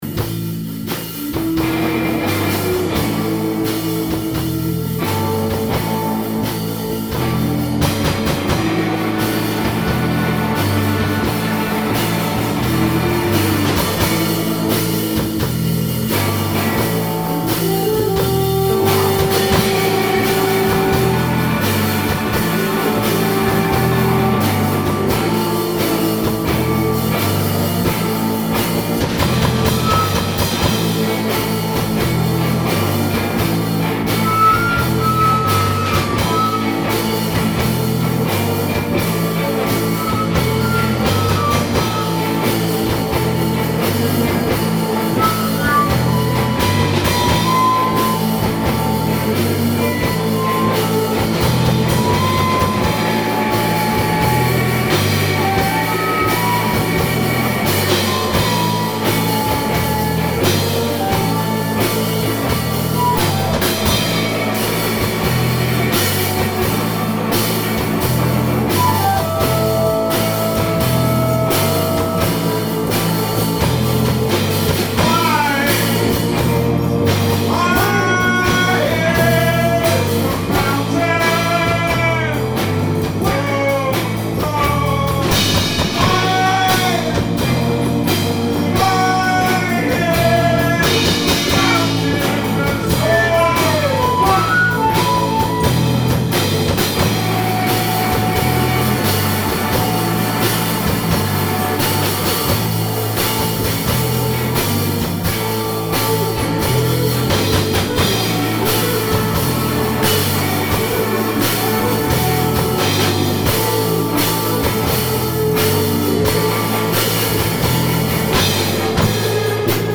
ALL MUSIC IS IMPROVISED ON SITE
Unexplained Music on the Moon voice/guitar
tympanis
drums
bass